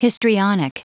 Prononciation du mot histrionic en anglais (fichier audio)
Prononciation du mot : histrionic
histrionic.wav